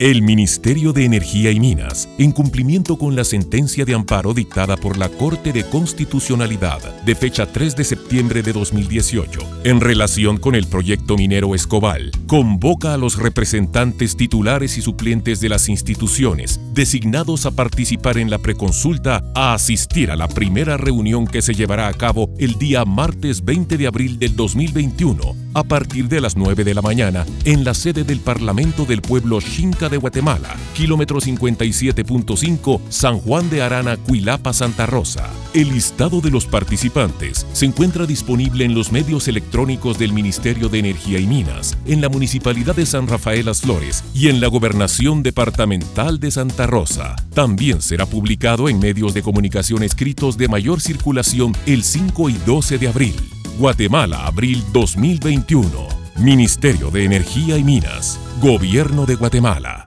Audio Convocatoria Radio Español